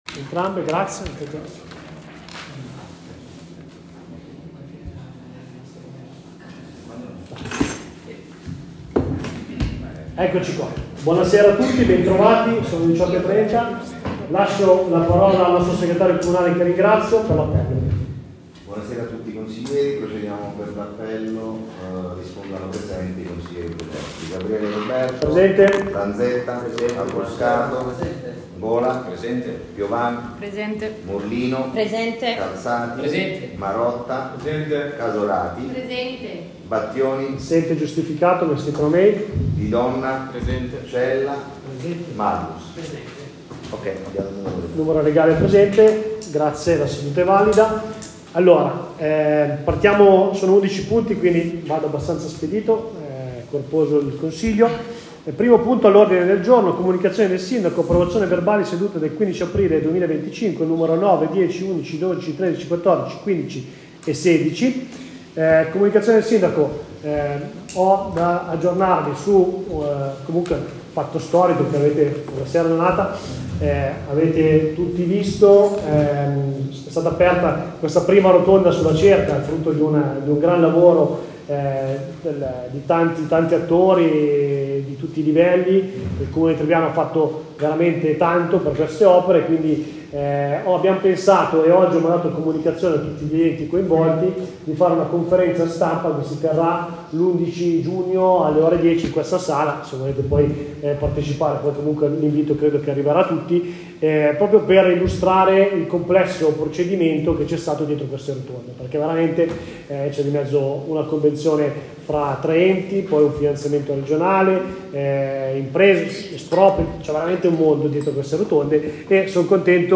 Consiglio comunale del 21 MAGGIO 2025 - Comune di Tribiano
Data 22/05/2025 Provenienza Ufficio SEGRETERIA GENERALE Descrizione n questa sezione sono disponibili le registrazioni delle sedute del Consiglio Comunale di Tribiano.